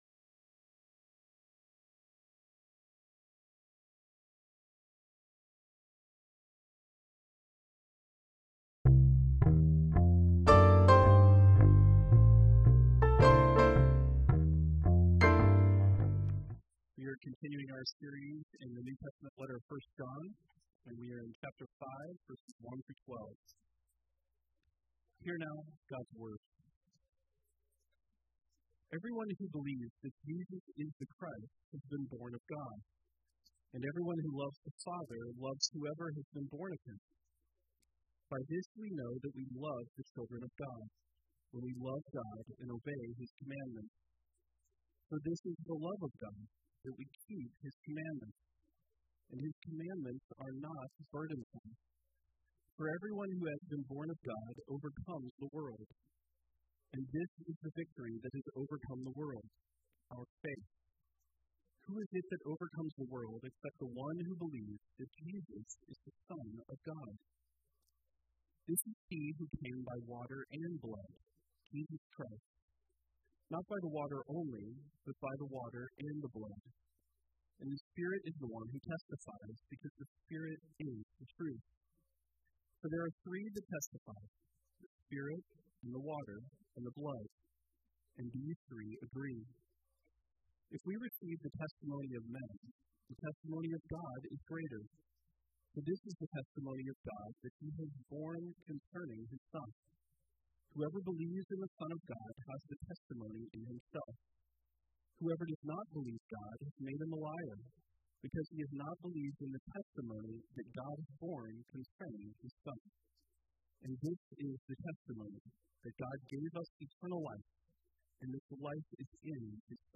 Passage: 1 John 5:1-12 Service Type: Sunday Worship « What Is Love?